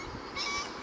徘徊しながら耳を澄ますと、あちこちからホロロが聞こえてくる。